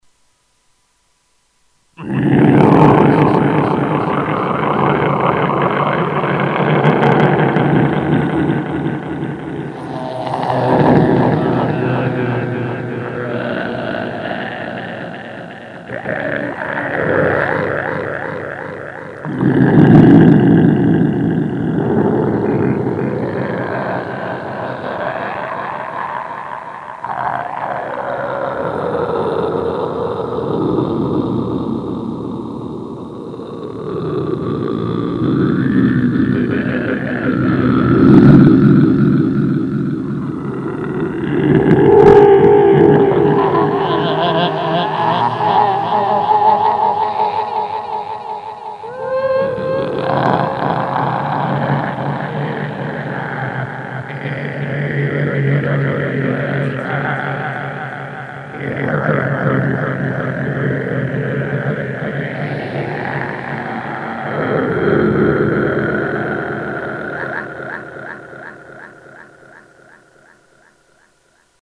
rychanie-besa-i-satany-15
• Категория: Звуки из ада
• Качество: Высокое